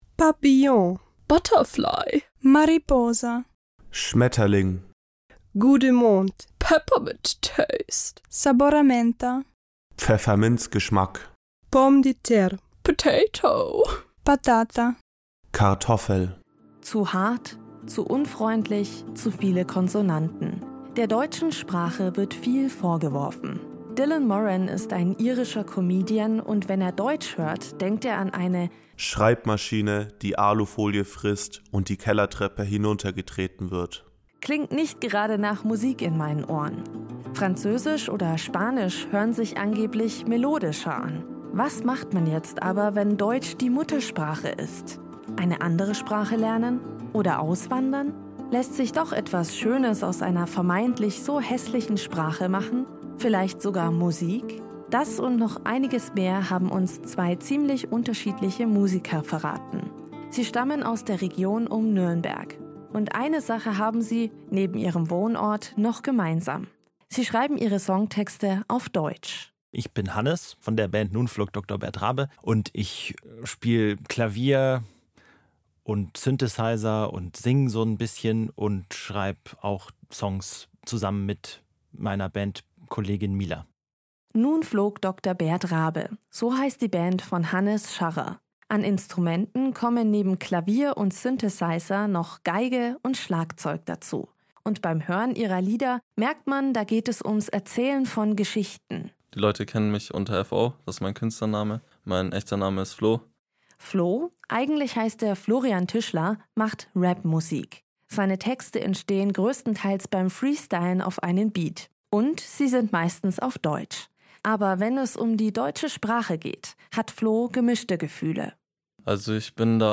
Das-Problem-mit-deutschen-Liedern.-Audio-Feature.mp3